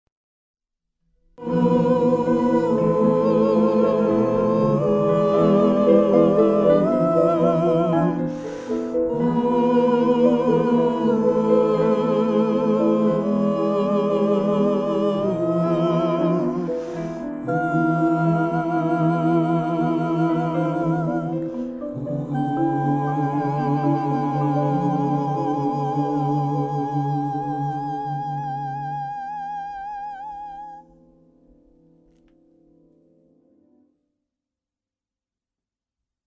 with singing and instrumental music.
marimba, percussion
piano, synthesizer, amadinda